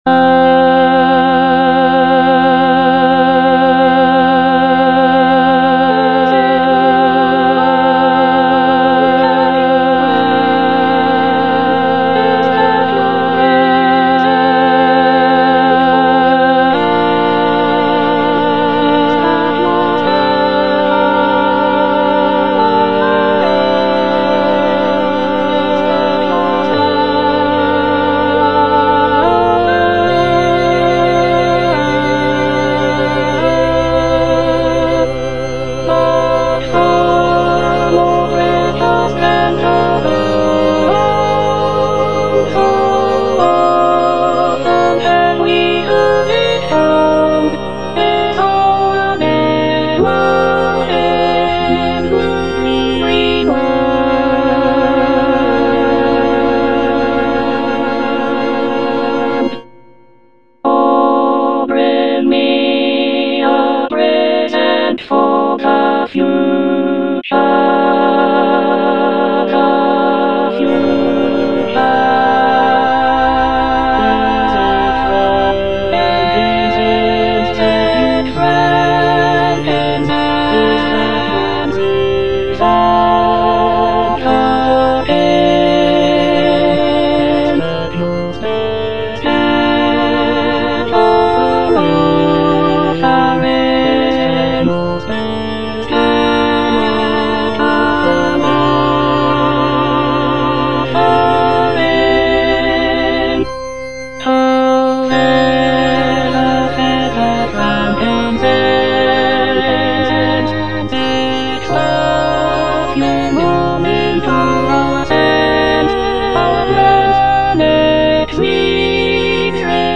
Alto II (Emphasised voice and other voices)
choral work